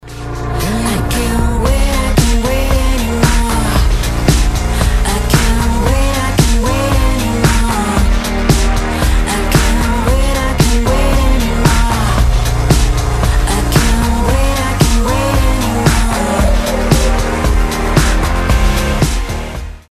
поп
женский голос
dance
vocal